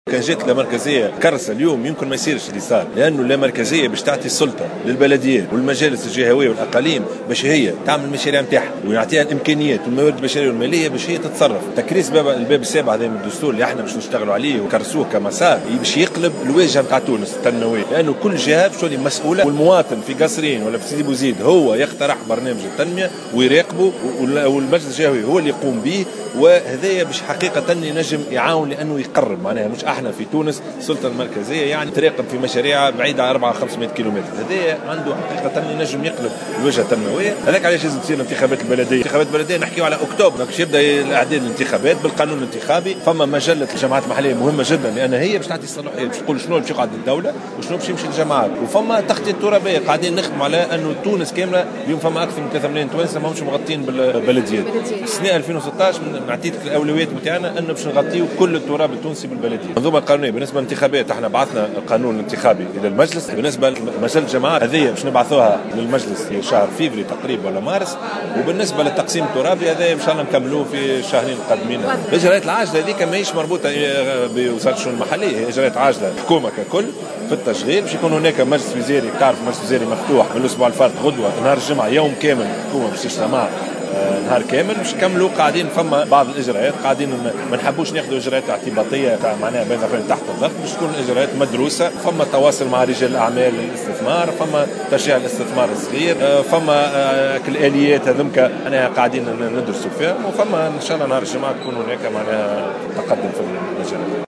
على هامش جلسة حوار عقدت مع رئيس الحكومة بالمجلس